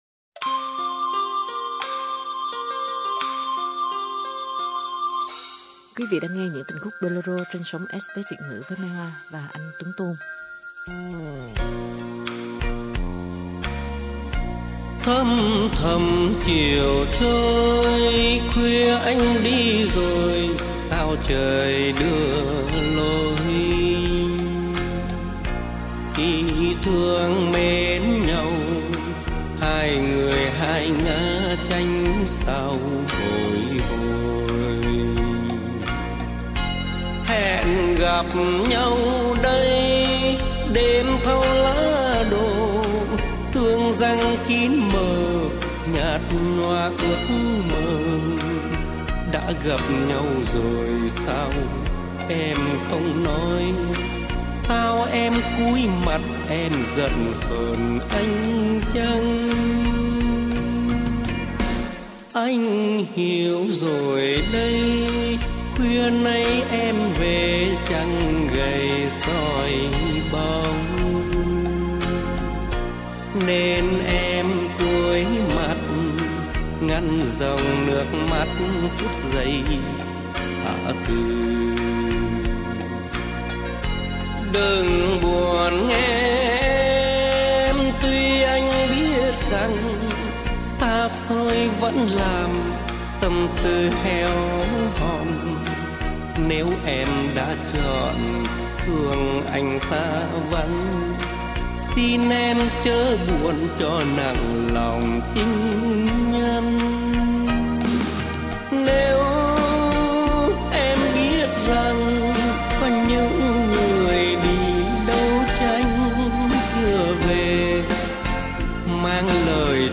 qua các giọng ca tài danh